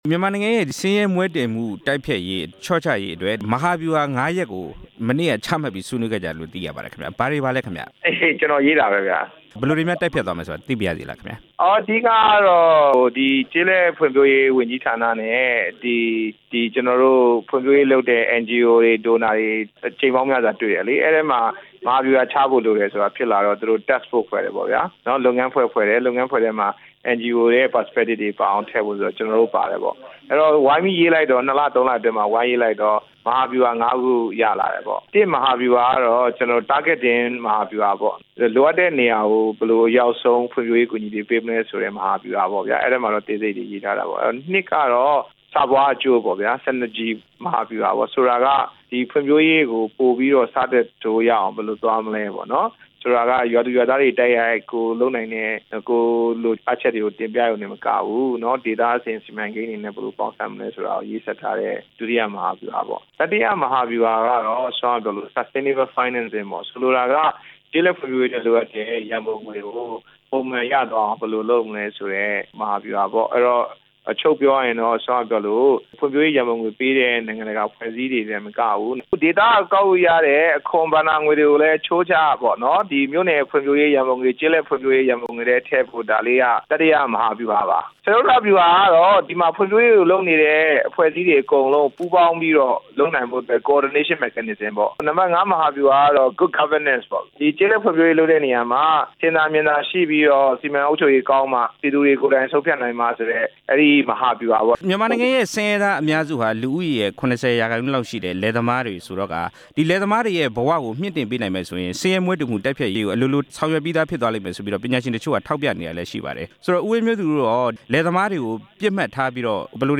ဆင်းရဲမွဲတေမှု တိုက်ဖျက်ရေး မဟာဗျူဟာ ၅ ရပ်အကြောင်း ဆက်သွယ်မေးမြန်းချက်